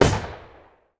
EXPLOSION_Arcade_16_mono.wav